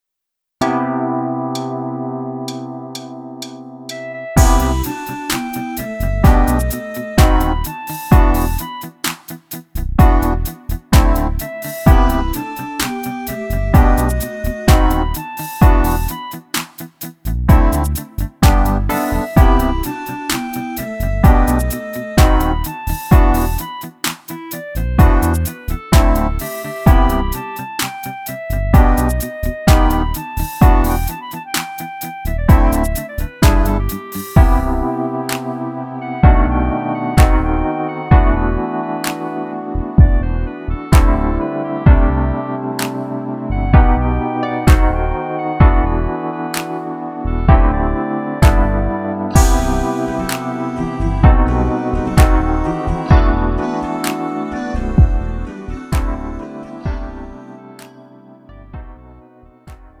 장르 가요 구분 Lite MR